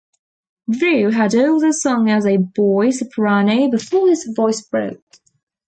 Pronúnciase como (IPA) /dɹuː/